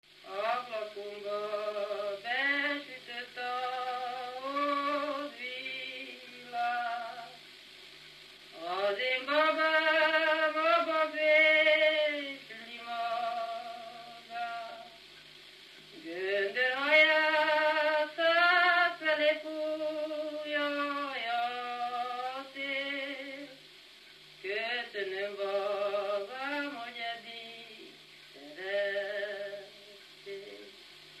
Erdély - Kolozs vm. - Válaszút
Stílus: 8. Újszerű kisambitusú dallamok
Kadencia: 2 (2) 4 1